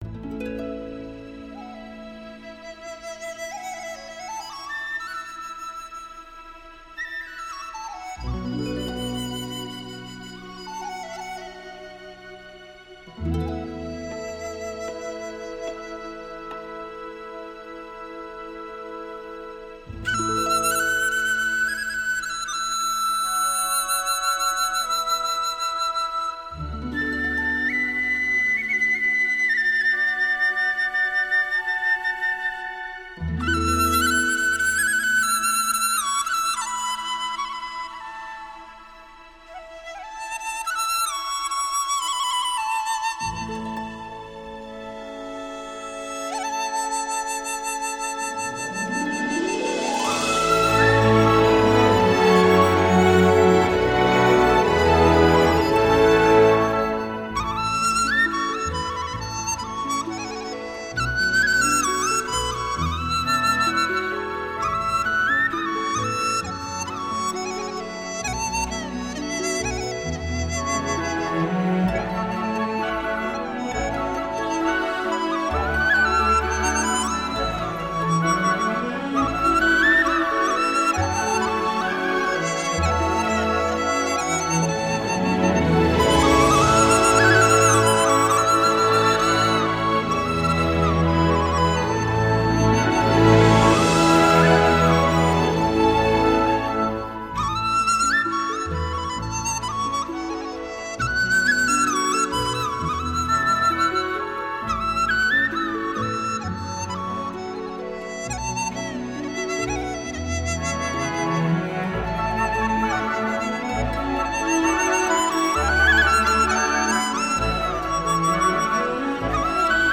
西洋交响乐演奏结合中国古典乐器
国内顶级录音棚之一--中国电视剧制作中心录音棚精心录制
赫哲族民歌
笛子